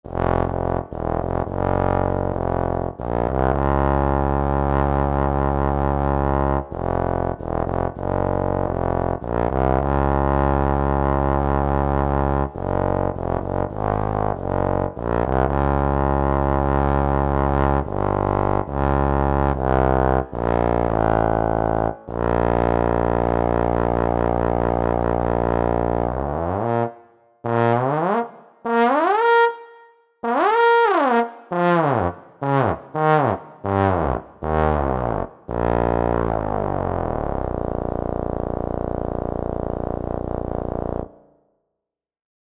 Туба Еb: Вложения Tuba Eb test.mp3 Tuba Eb test.mp3 1,6 MB · Просмотры: 2.101 Последнее редактирование: 26 Фев 2020